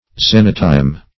Xenotime \Xen"o*time\, n. [Gr. xeno`timos honoring guests or